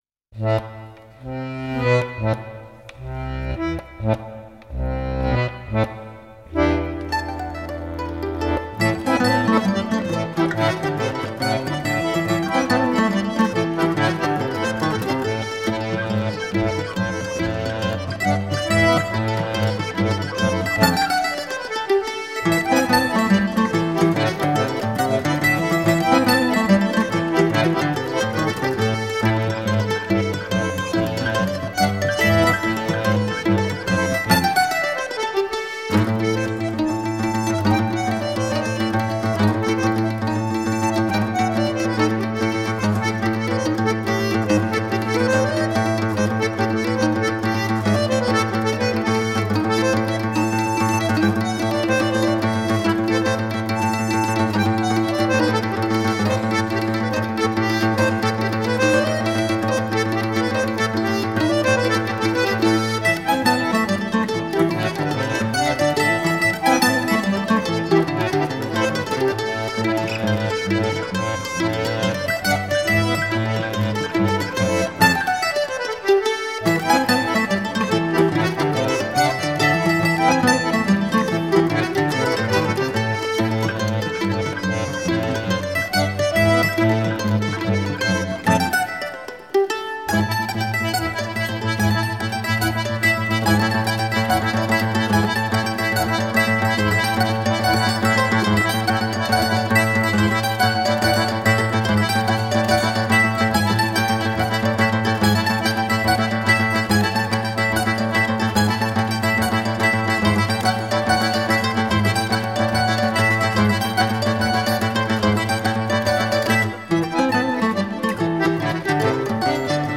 Musiques à danser
accordéon diatonique, percus
guitare, bouzouki